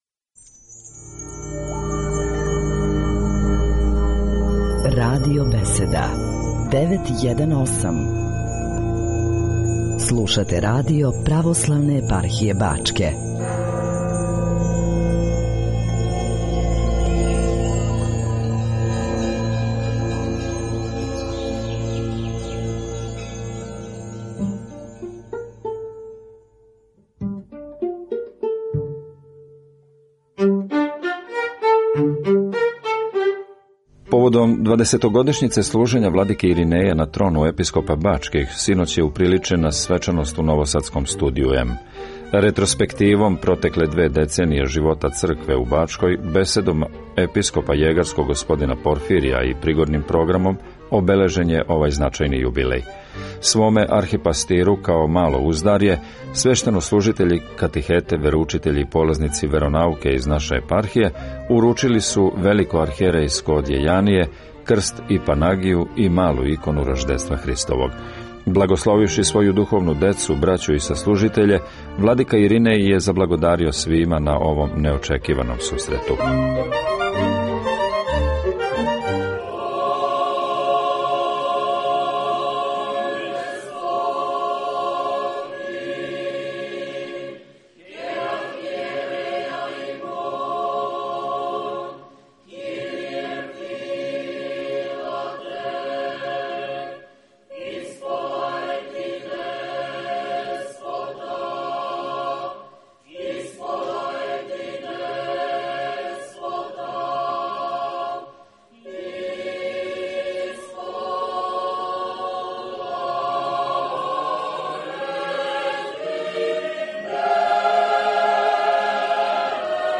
Поводом двадесетогодишњице служења Владике Иринеја на трону Епископâ бачких, у четвртак, 23. децембра 2010. године, уприличена је свечаност у новосадском Студију М.
Ретроспективом протекле две деценије живота Цркве Божје у Бачкој, беседом Епископа јегарског господина Порфирија и пригодним програмом обележен је овај значајни јубилеј.